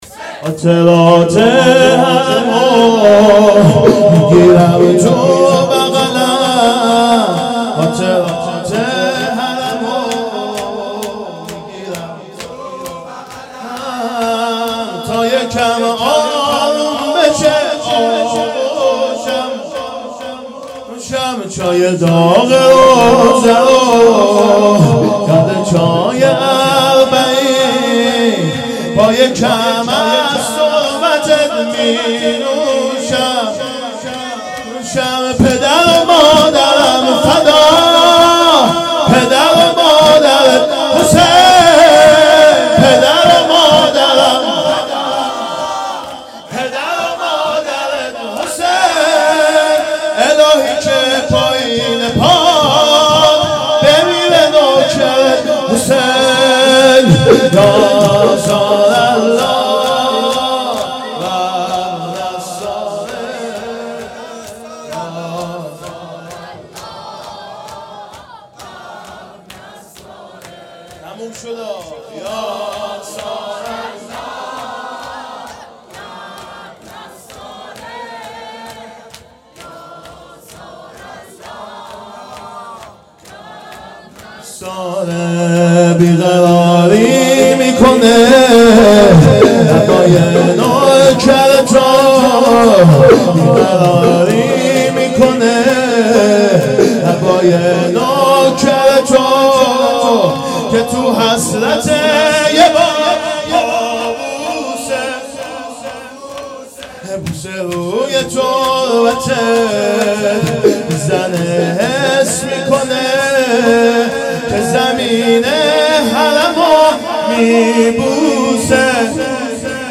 خاطرات حرمو تو بغل میگیرم _ شور
محرم 1440 _ شب پنجم